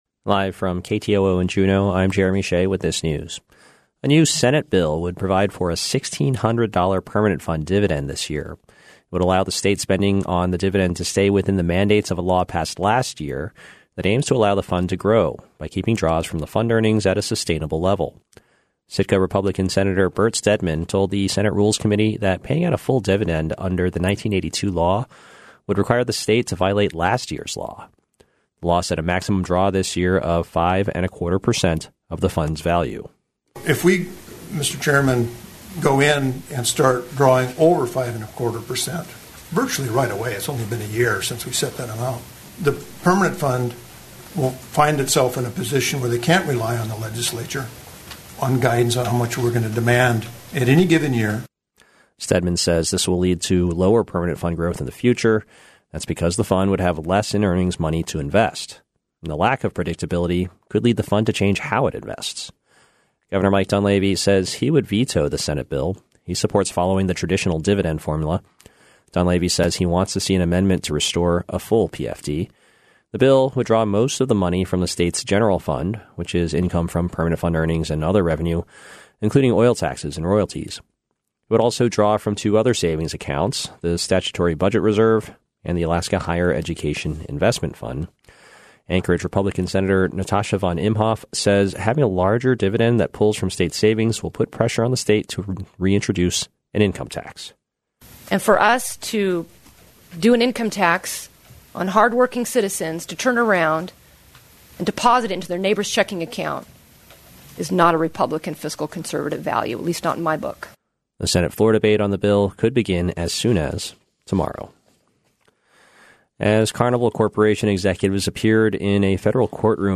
Newscast – Monday, June 3, 2019